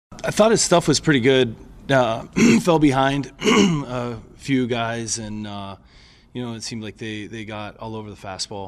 Pirates manager Donnie Kelly says the Cubs got to Braxton Ashcraft the second time through the order.